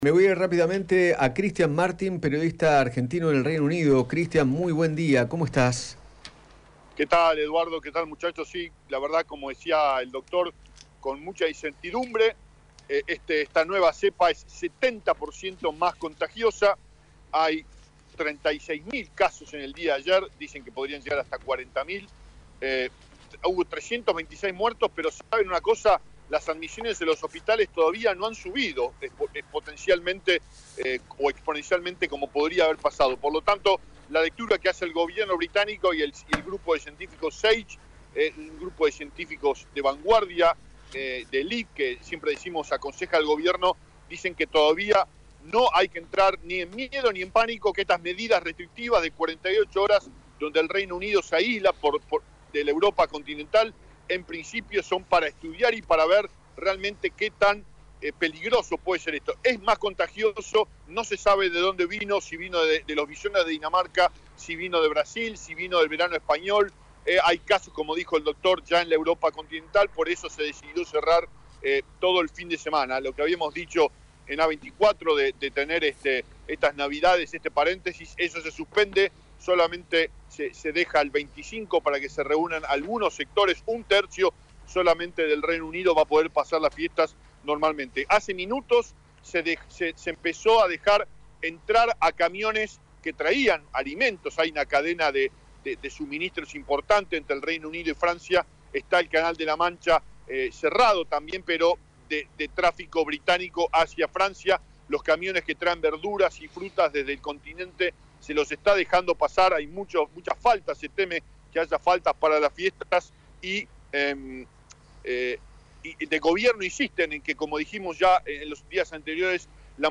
periodista en Reino Unido